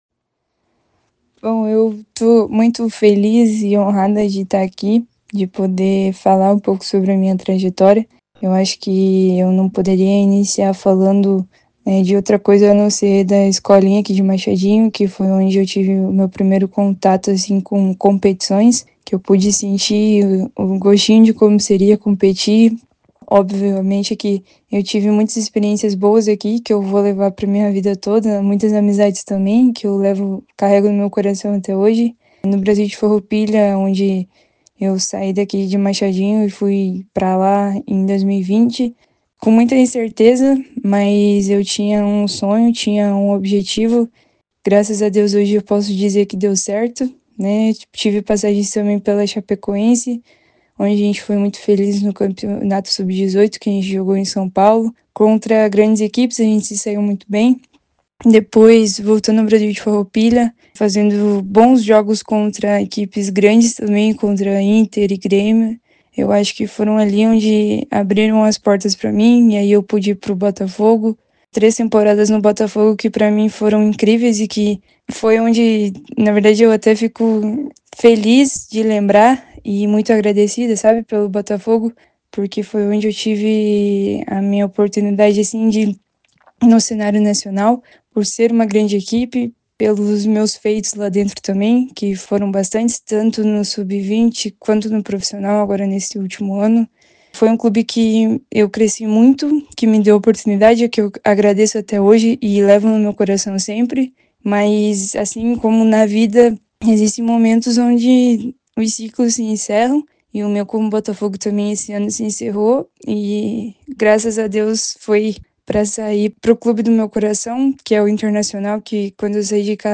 falou com a Rádio Club FM 96,7